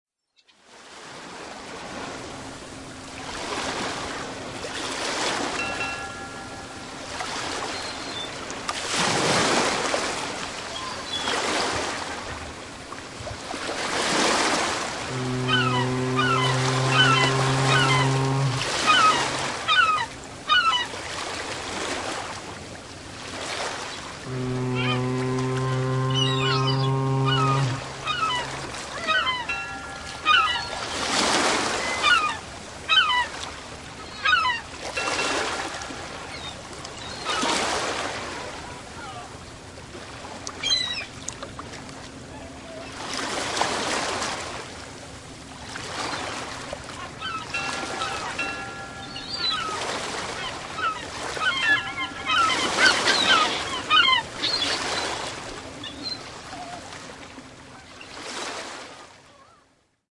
Download Ocean sound effect for free.
Ocean